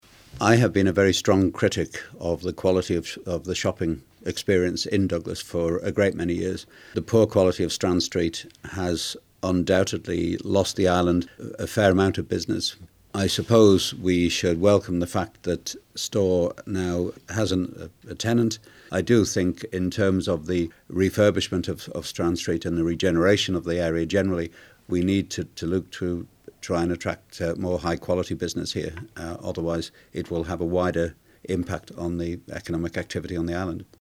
However, this has started to beg serious questions surrounding he quality of shopping in the Isle of Man, which was echoed by the Chief Minister Allan Bell who spoke to 3FM (see audio).